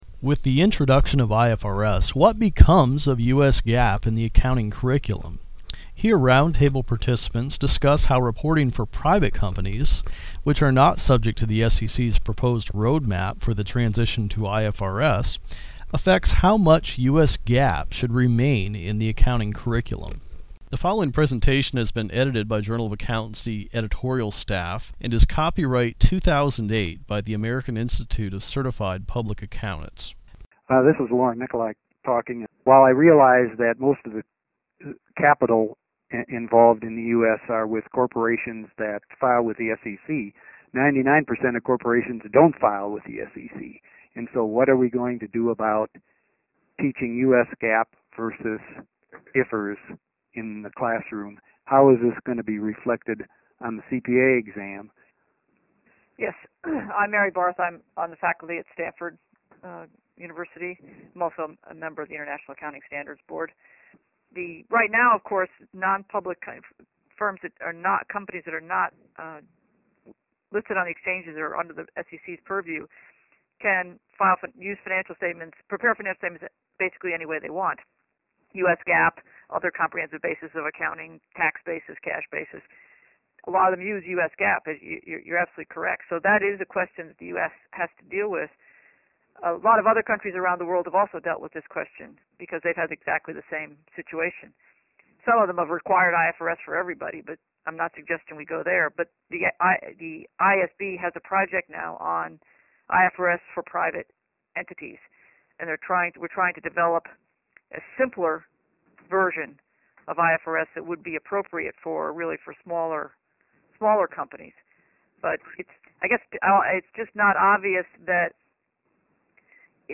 To get a sense of the effect IFRS is having at colleges and universities, the JofA hosted a virtual round table, gathering eight accounting professors from around the country by conference call (for a detailed list of panelists, see below).
Here we present audio clips from the round-table discussion, which is detailed in the December issue of the JofA: